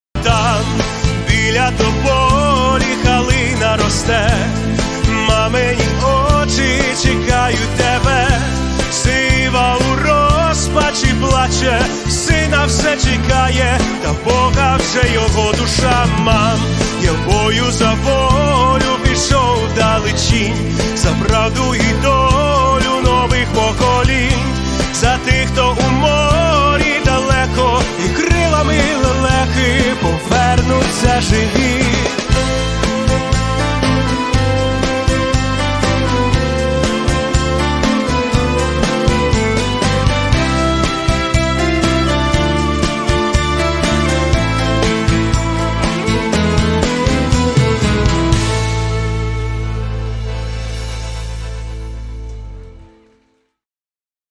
• Качество: 320, Stereo
мужской голос
спокойные